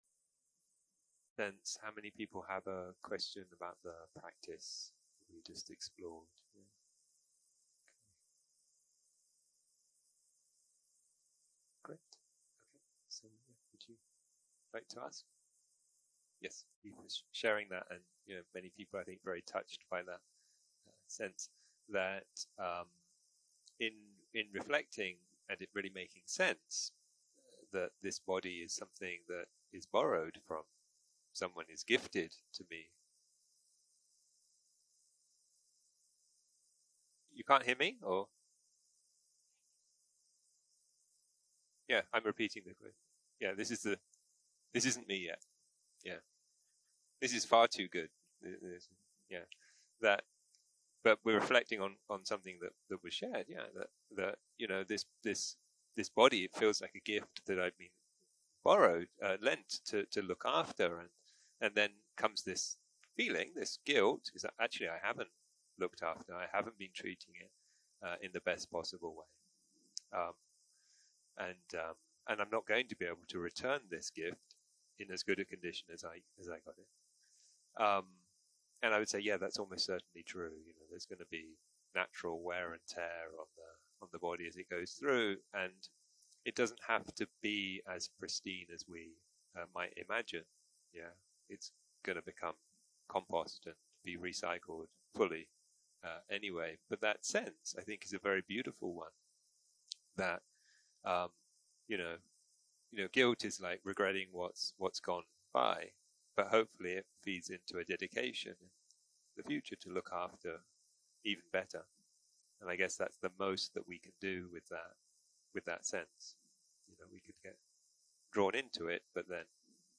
סוג ההקלטה: שאלות ותשובות